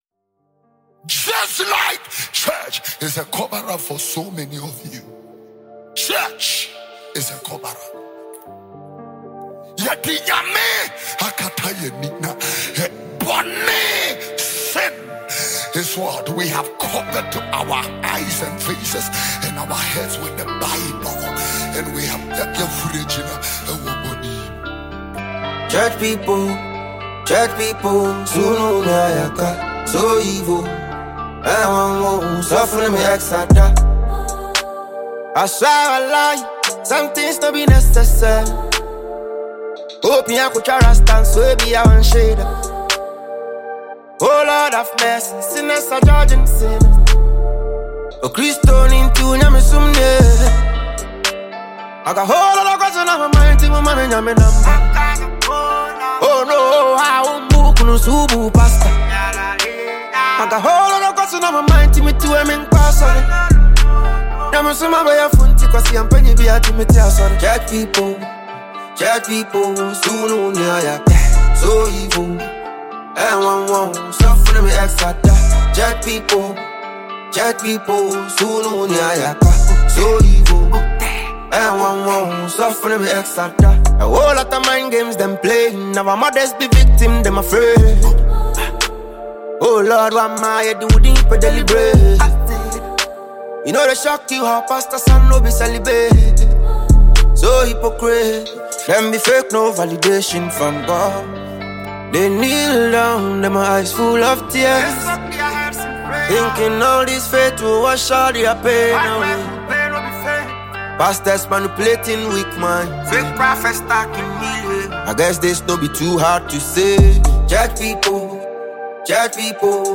crafting a unique sound within the Afro Fusion genre.